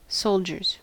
Uttal
Synonymer troops Uttal US Okänd accent: IPA : /ˈsəʊl.dʒəz/ Ordet hittades på dessa språk: engelska Ingen översättning hittades i den valda målspråket.